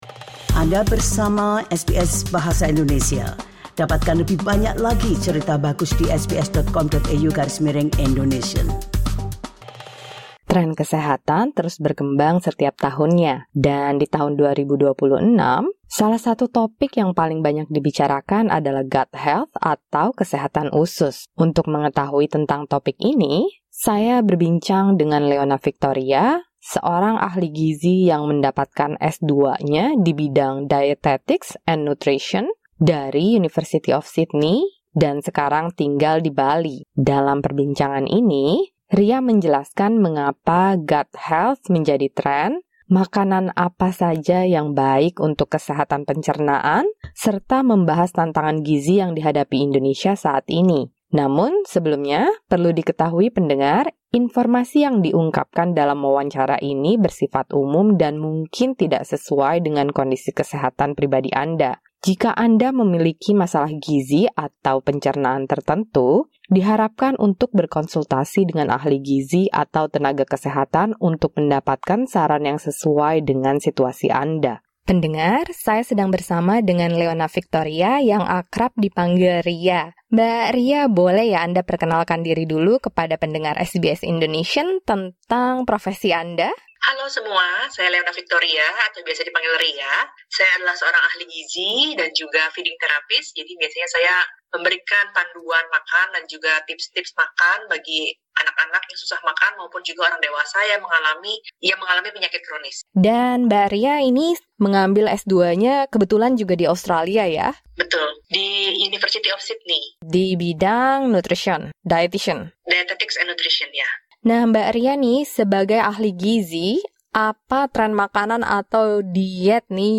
Note: The information shared in this interview is of general nature and may not suit your personal situation.